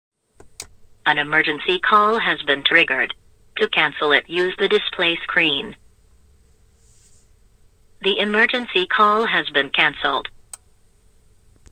As the countdown occurs, there is a voice prompt stating "An emergency call has been triggered, to cancel it use the display screen."
ecall_prompt_sound.mp3